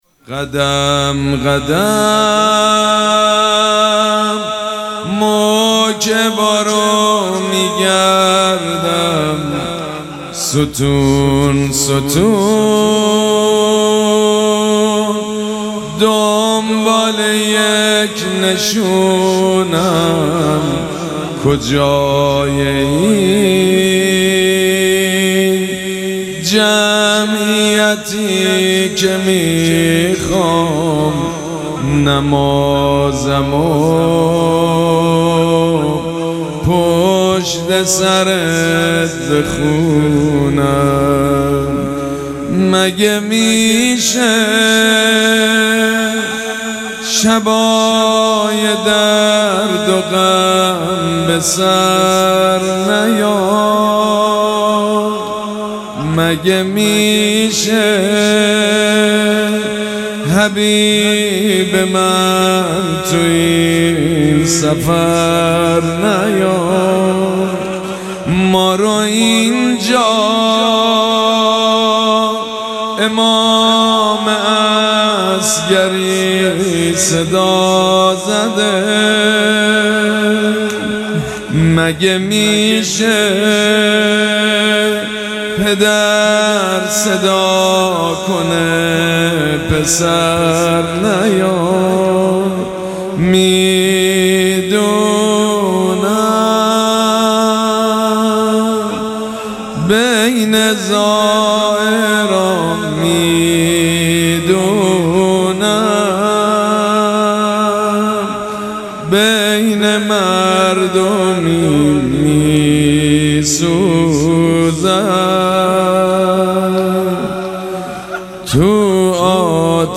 شب اول مراسم عزاداری اربعین حسینی ۱۴۴۷
زمزمه
مداح
حاج سید مجید بنی فاطمه